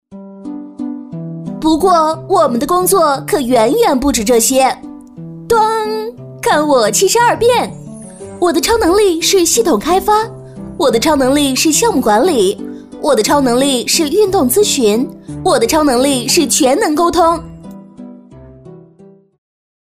【飞碟说】超能力女25-幽默诙谐
【飞碟说】超能力女25-幽默诙谐.mp3